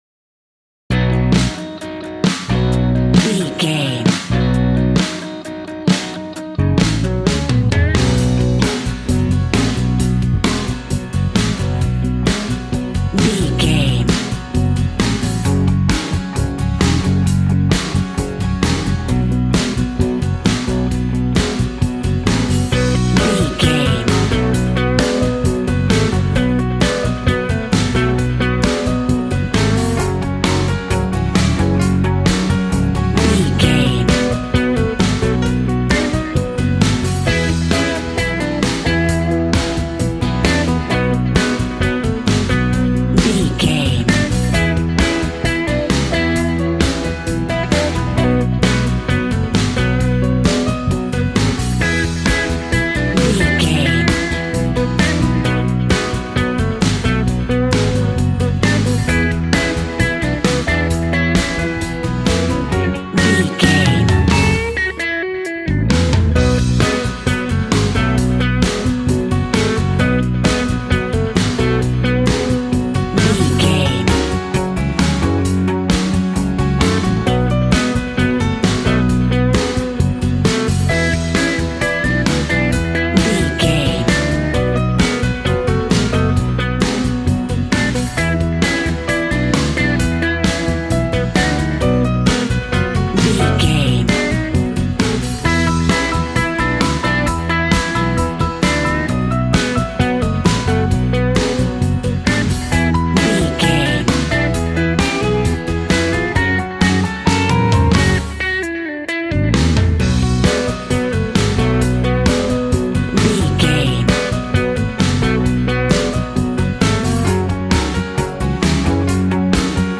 Ionian/Major
groovy
powerful
electric guitar
bass guitar
drums
organ